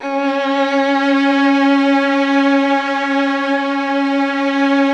MELLOTRON .3.wav